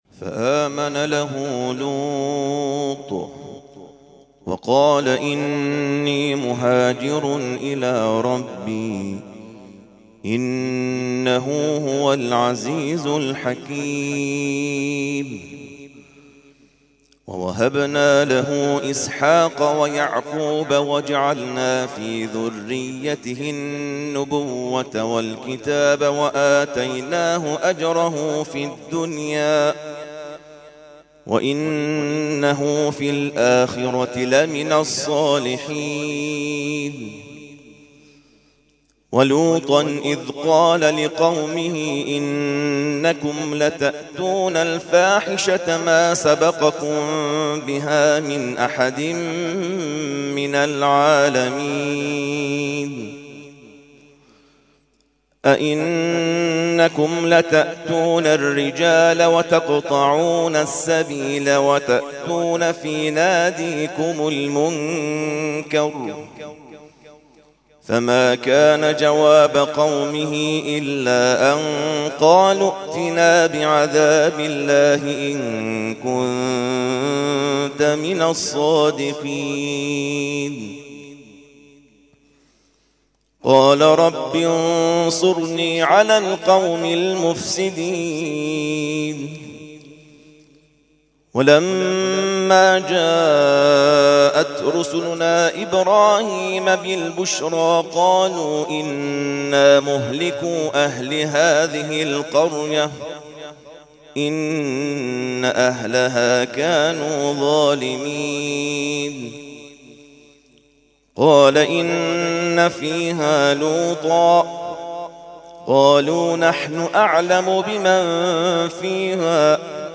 ترتیل خوانی جزء ۲۰ قرآن کریم در سال ۱۳۹۶